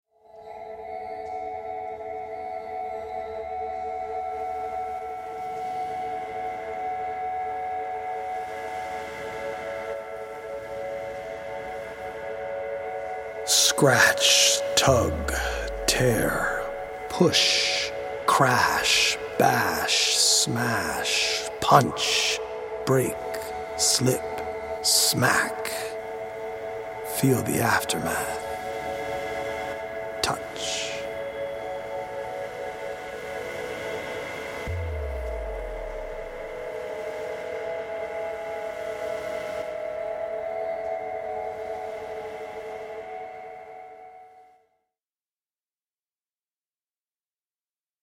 poetic journey
healing Solfeggio frequency music